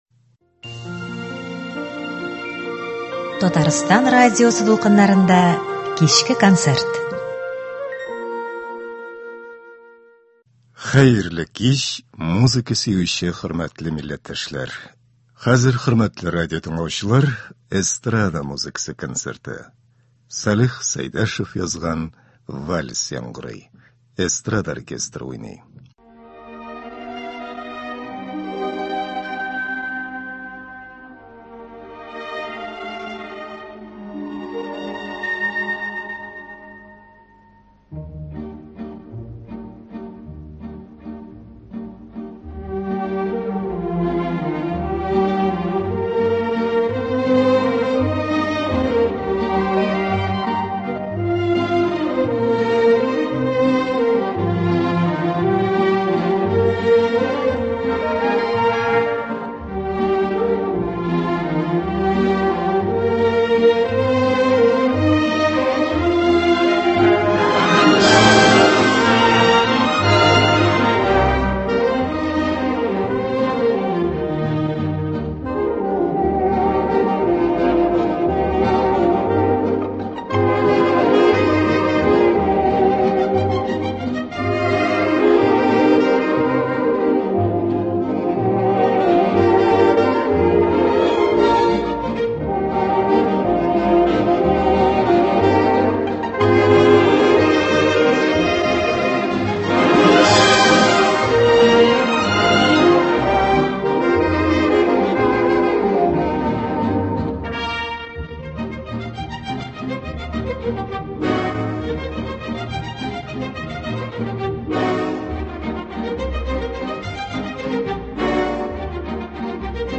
Җомга кичен яраткан җырлар белән үткәрик